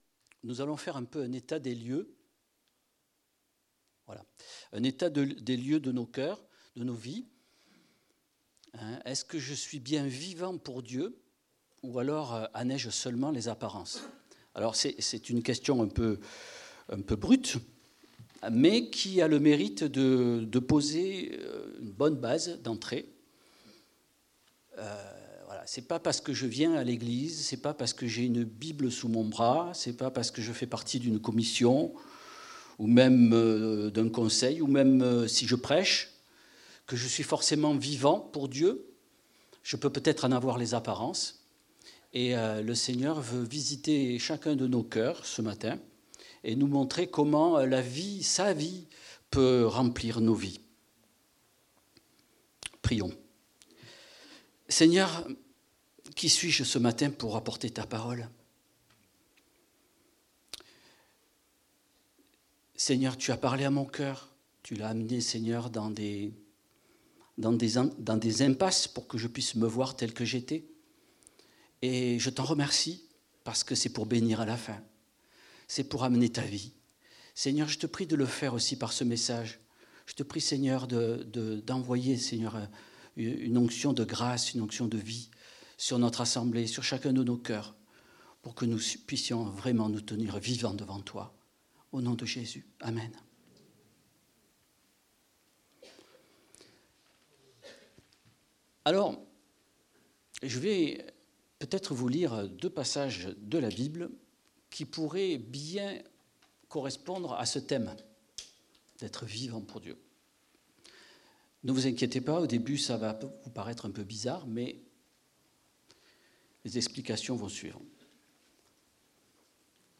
Culte du dimanche 01 février 2026